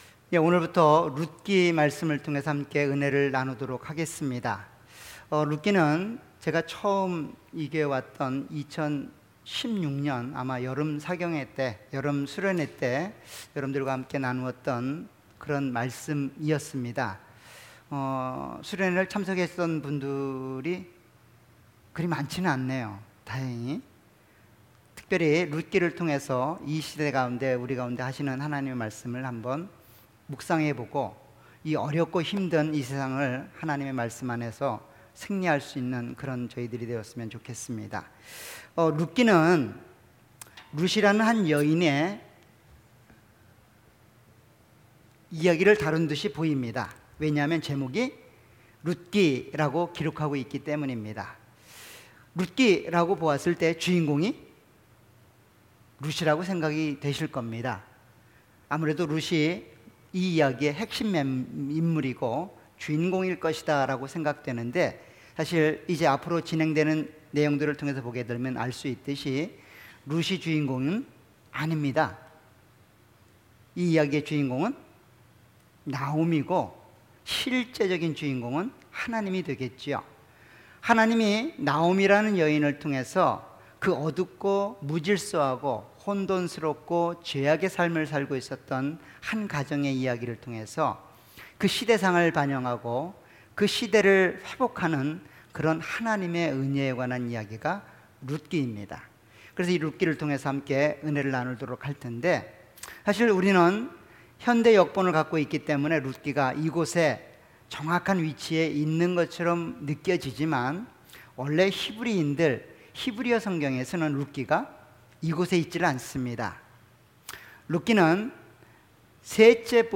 All Sermons
Series: 수요예배.Wednesday